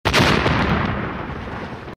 vzryv.mp3